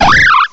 cry_not_bunnelby.aif